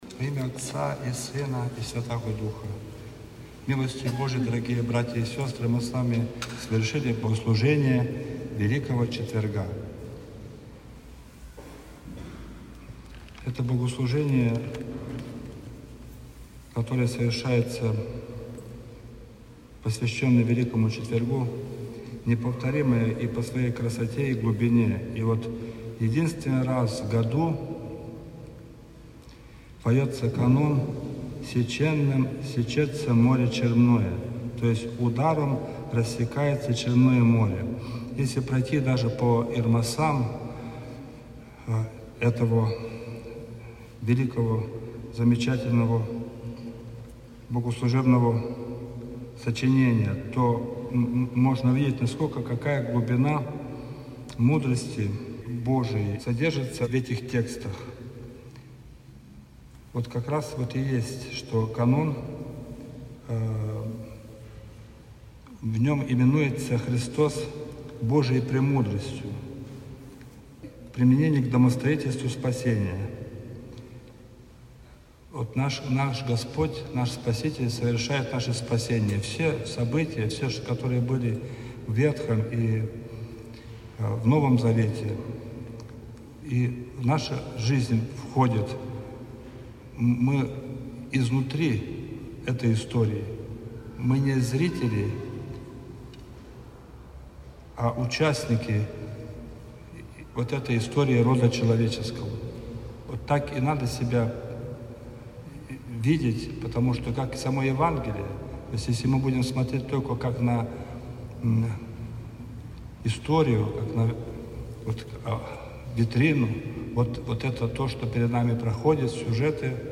Утреня. Общая исповедь - Храмовый комплекс святого праведного Иоанна Кронштадтского на Кронштадтской площади
По традиции после богослужения была совершена общая исповедь.
Общая-исповедь.mp3